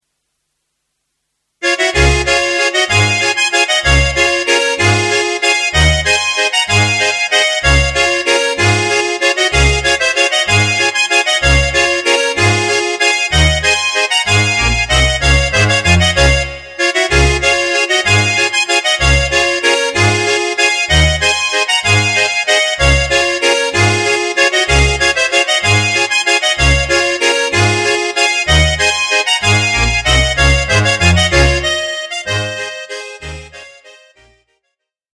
Db-Dur